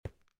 stonestep5.ogg